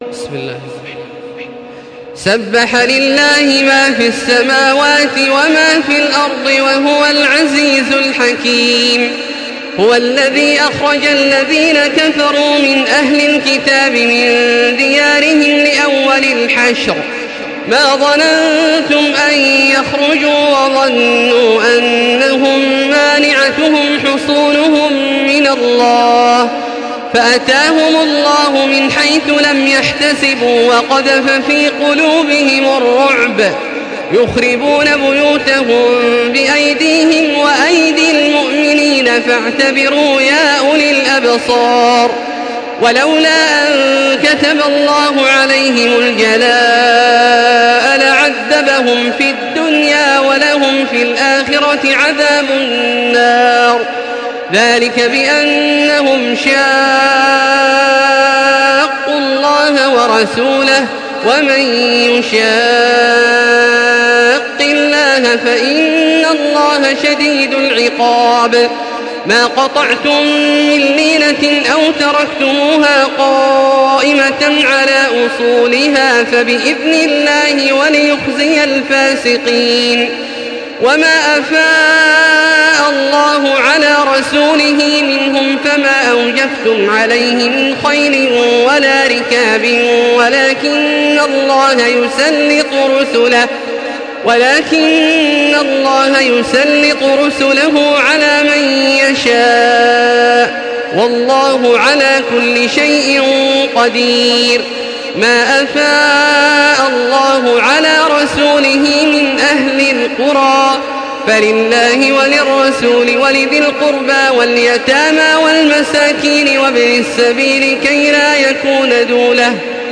تحميل سورة الحشر بصوت تراويح الحرم المكي 1435
مرتل